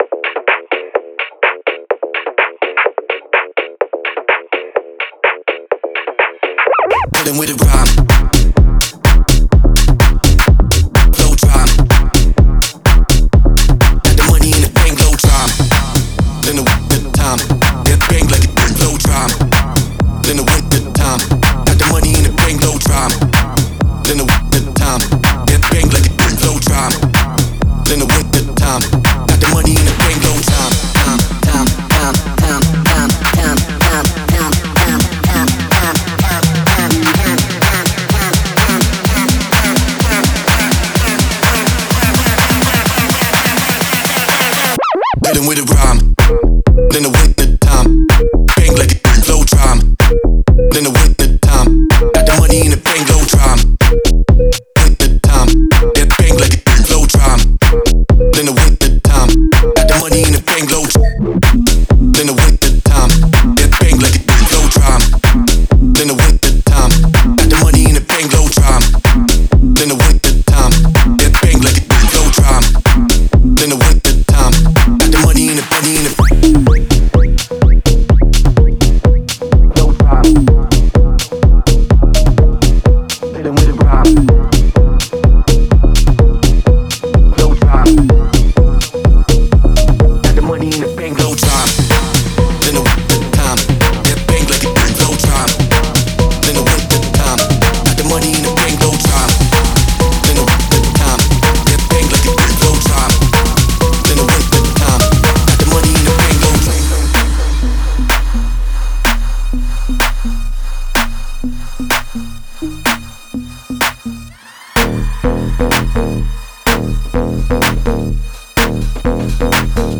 это энергичная трек в жанре электронной танцевальной музыки